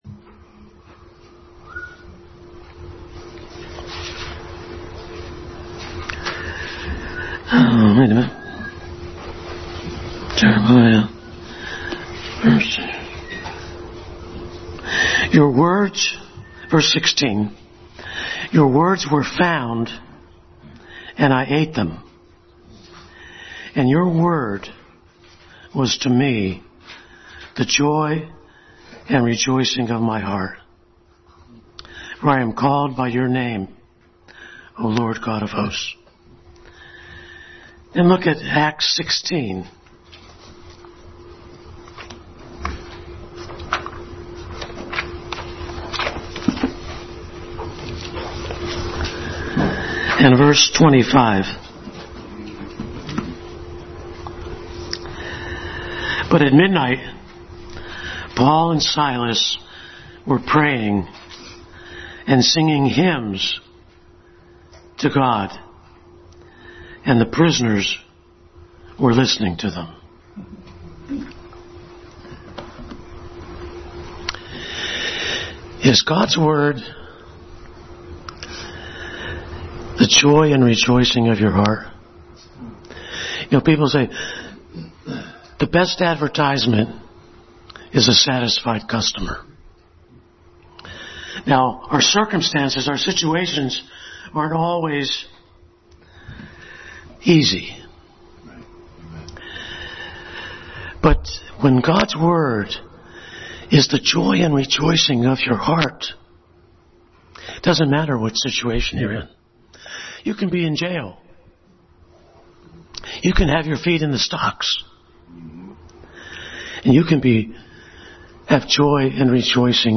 Passage: Jeremiah 15:16-17, Acts 16:25, Genesis 22:2, John 3:16 Service Type: Family Bible Hour